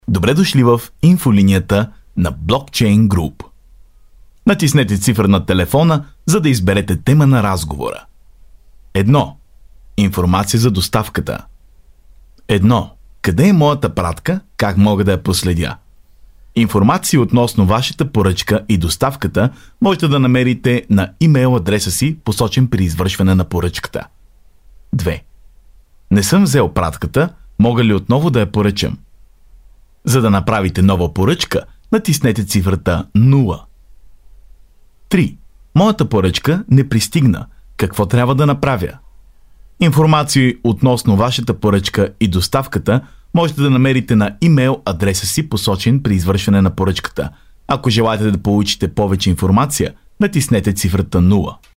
男保加利亚03